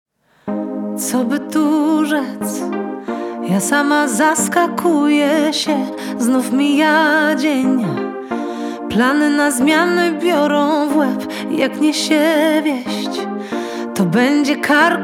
Perkusja
Gitary
Instrumenty klawiszowe, bass, instr. perkusyjne, piano